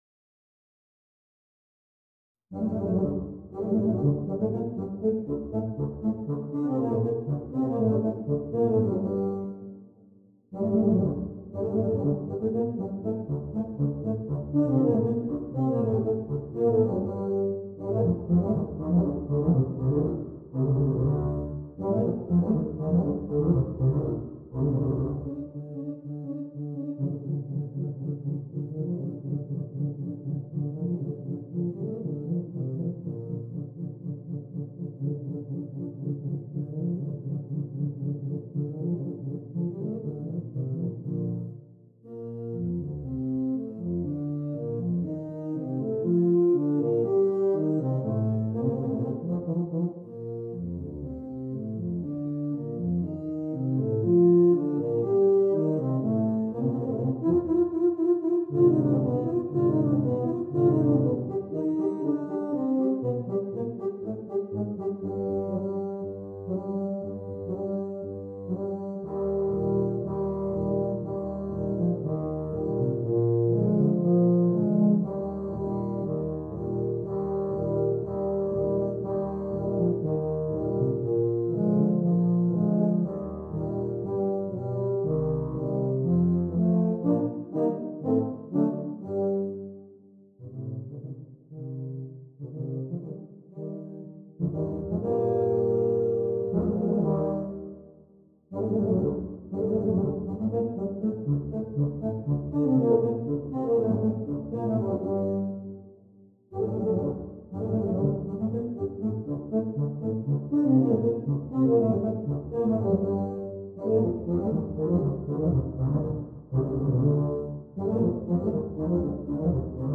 2 Euphoniums, 2 Tubas
This is a flashy piece and everyone gets a good part.